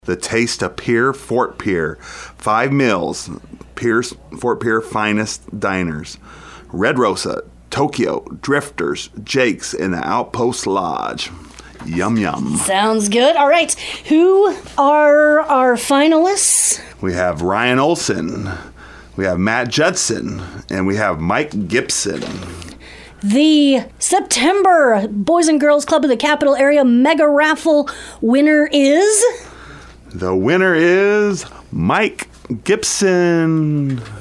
The three finalists for the Boys and Girls Club of the Capital Area’s September Mega Raffle drawing were picked this (Wed.) morning during the KGFX morning show.